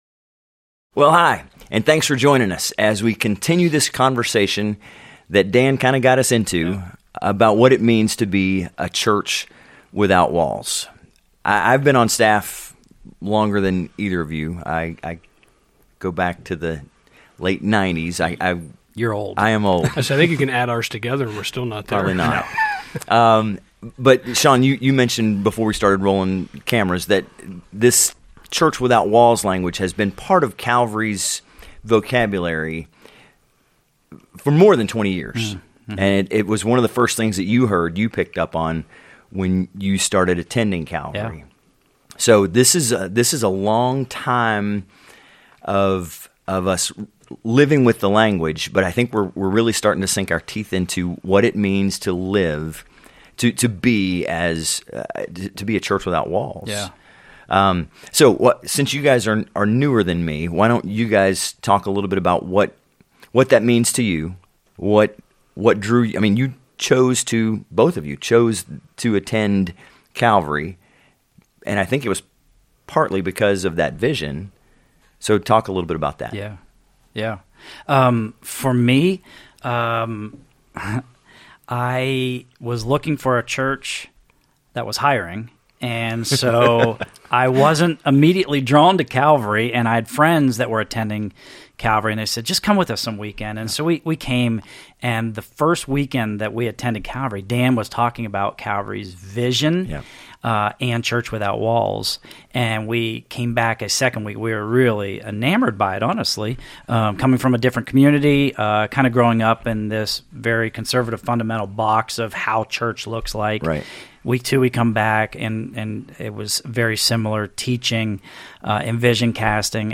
Ep 14. State of the Church | A Conversation about our Church without walls vision | Calvary Portal | Calvary Portal